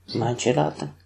Pronunciation[maːnʲɕi laːtəŋ]
Northern_Mansi_language,_Ob_dialect,_mansi_language_pronunciation.mp3